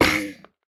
Minecraft Version Minecraft Version snapshot Latest Release | Latest Snapshot snapshot / assets / minecraft / sounds / mob / turtle / hurt4.ogg Compare With Compare With Latest Release | Latest Snapshot
hurt4.ogg